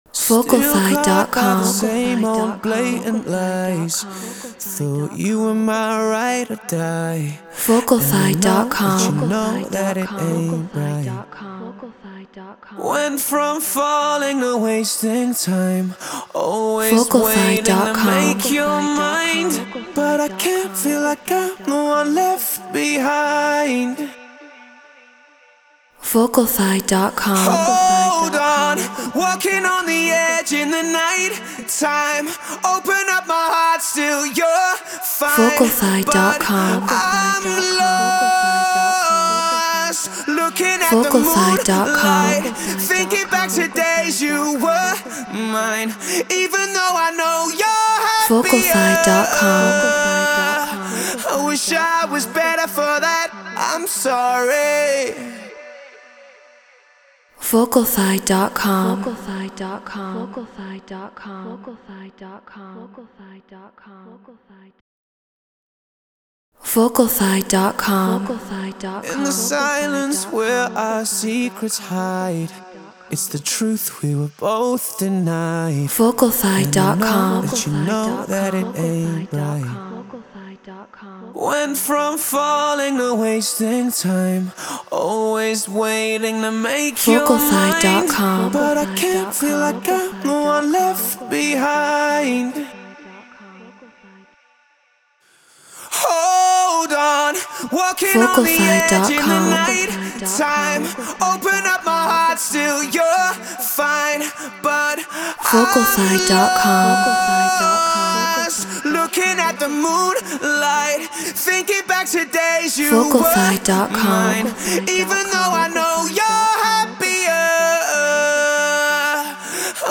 Future Bass 140 BPM Dmaj
Human-Made
Neumann TLM 103 Apollo Twin X Pro Tools Treated Room